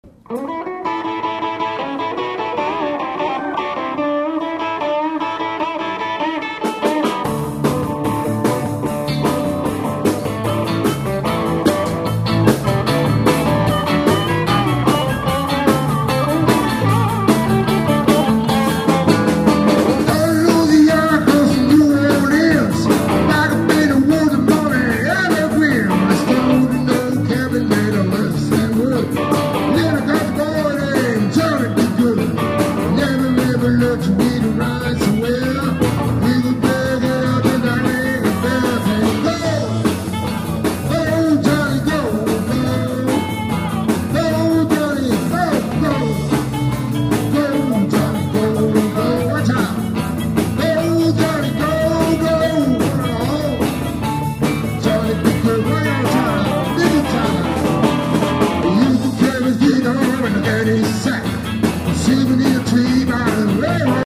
harmonicas
Des extraits, (30 secondes environ) du concert enregistré le 3 Mars 2000
au Relais de la Reine Margot (Longvic, Côte d'or) :